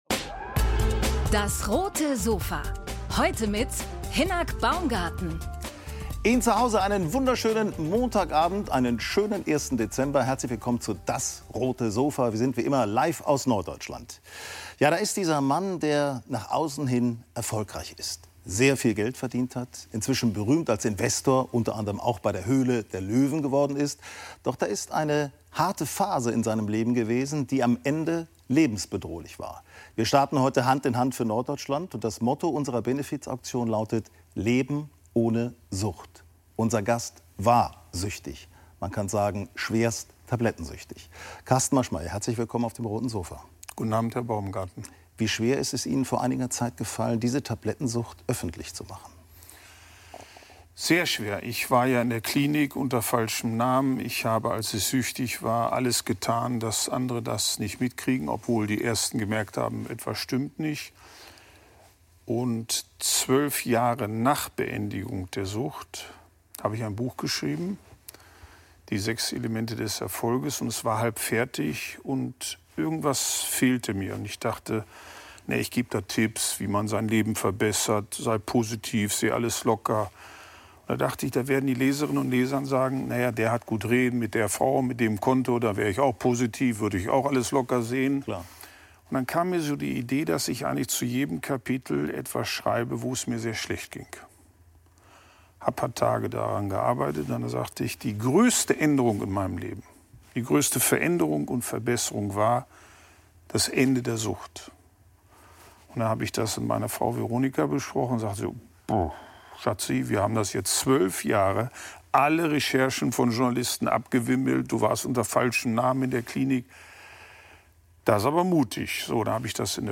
Unternehmer Carsten Maschmeyer im Talk über seine Medikamentensucht ~ DAS! - täglich ein Interview Podcast
Jahrelang kämpfte der Unternehmer gegen eine Medikamentensucht, die ihn bis an den Rand der Selbstzerstörung bringt. Auf dem Roten Sofa erzählt er darüber.